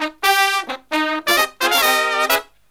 Index of /90_sSampleCDs/USB Soundscan vol.29 - Killer Brass Riffs [AKAI] 1CD/Partition D/03-133PERFS1